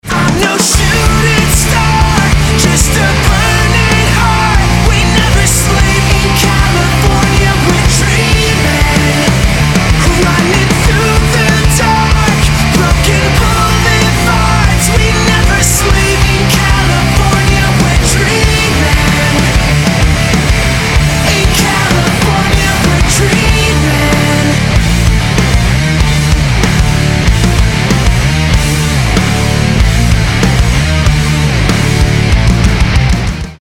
мужской вокал
громкие
alternative
Rapcore